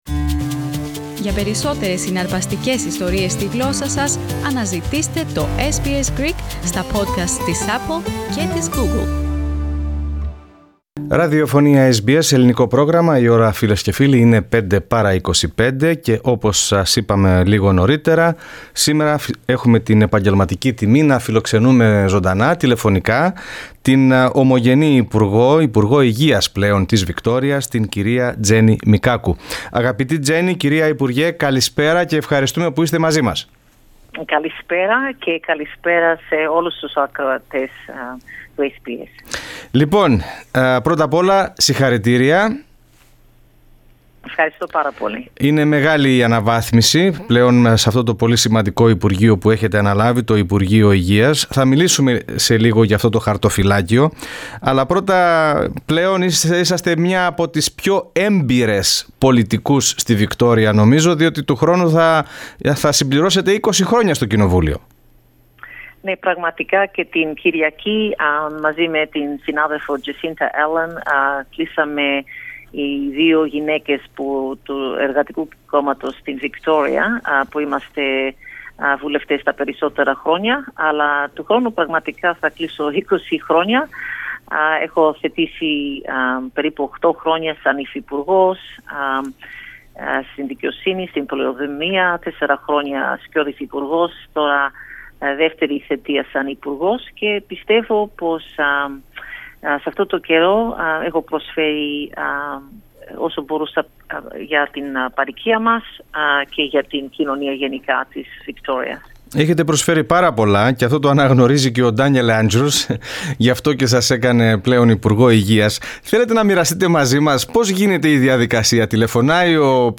Jenny Mikakos has been appointed as the new Minister for Health and Ambulance Services in the returned Andrews Labor Government. SBS Greek, spoke with ms Mikakos.